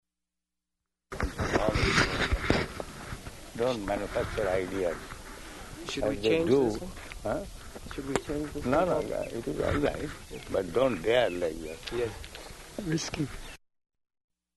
Morning Walk [partially recorded]
Type: Walk
Location: Māyāpur